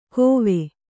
♪ kōve